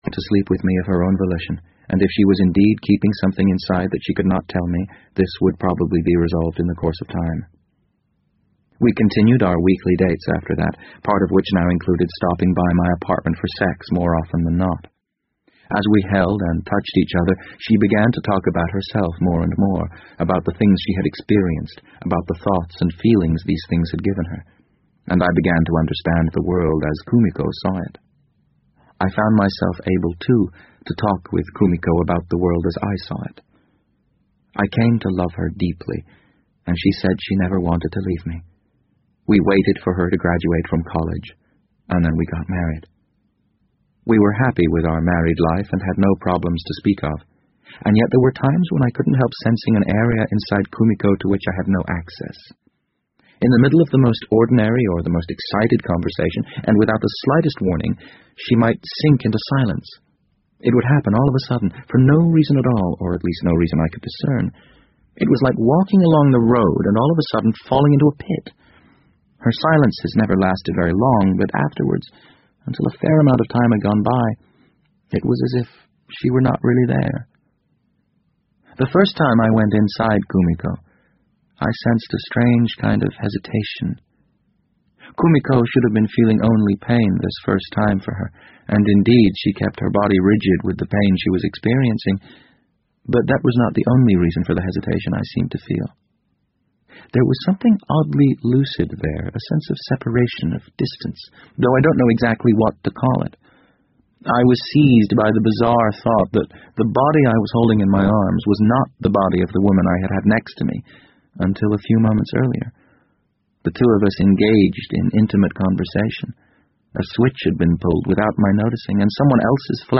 BBC英文广播剧在线听 The Wind Up Bird 006 - 13 听力文件下载—在线英语听力室